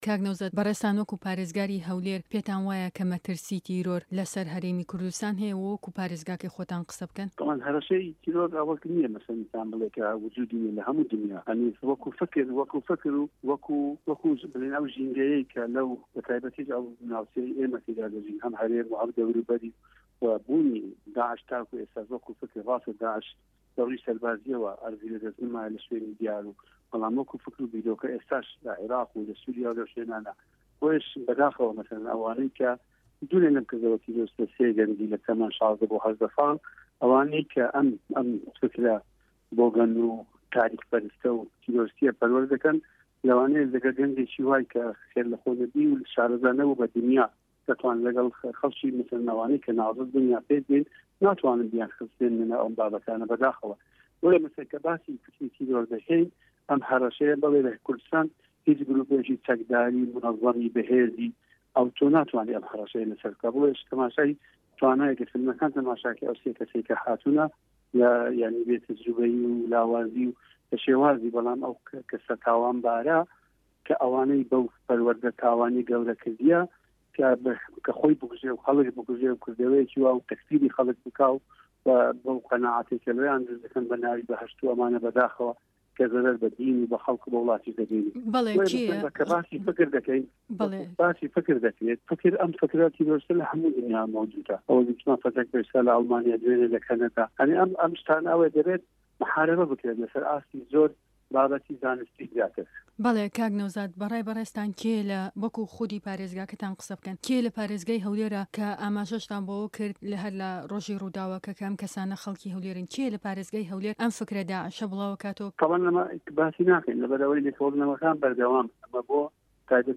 ده‌قی وتووێژه‌كه‌ی